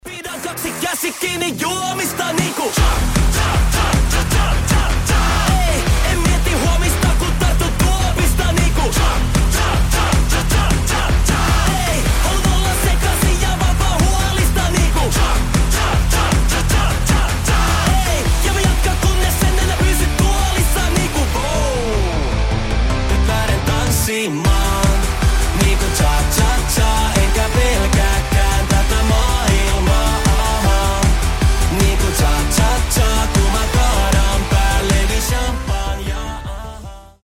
танцевальные , рок